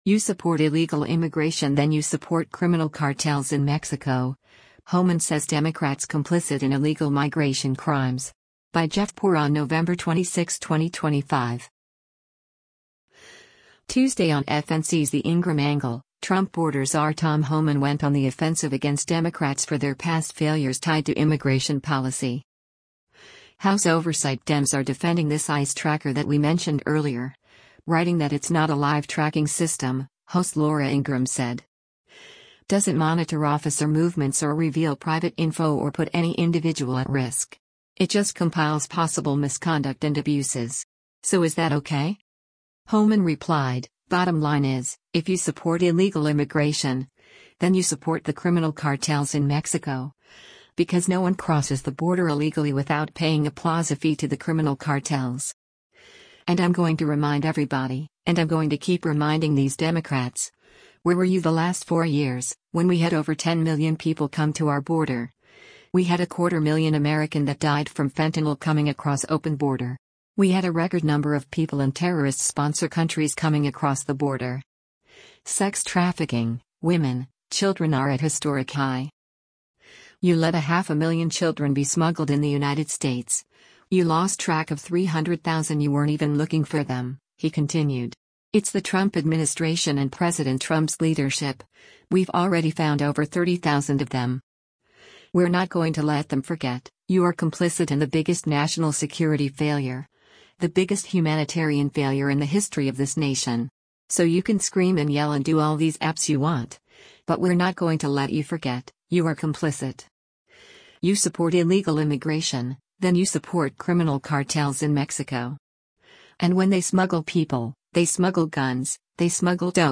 “House Oversight Dems are defending this ICE tracker that we mentioned earlier, writing that it’s not a live-tracking system,” host Laura Ingraham said.